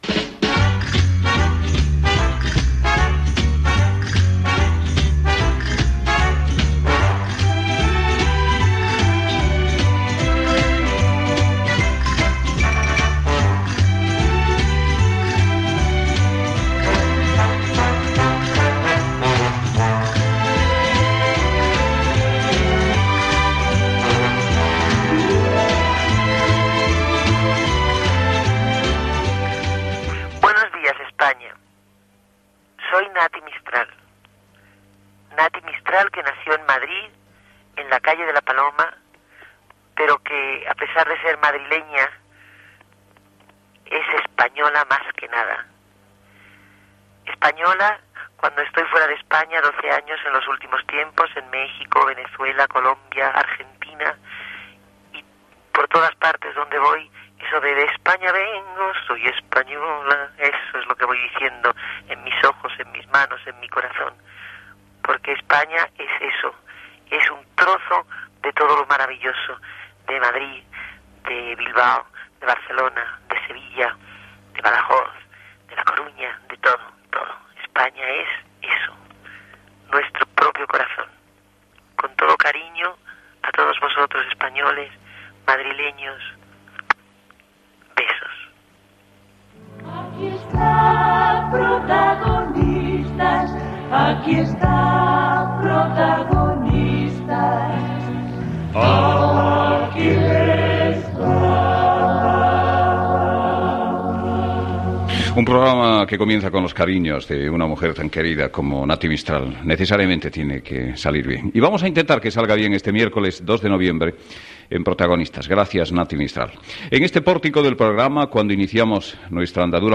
Sintonia de "Protagonistas", el "buenos días España" amb la cantant Nati Mistral, indicatiu del programa, felicitació a la Reina Sofia en el dia del seu aniversari
Info-entreteniment